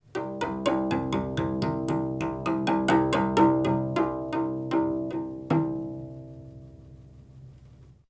Source: Col legno as before (10:25-12:30)
Col_Legno.aiff